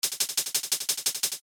Let’s adjust the length by giving it a tight envelope. Find the ADSR envelope of your sampler and shorten the Decay.